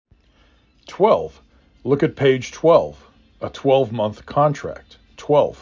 6 Letters, 1 Syllable
t w e l v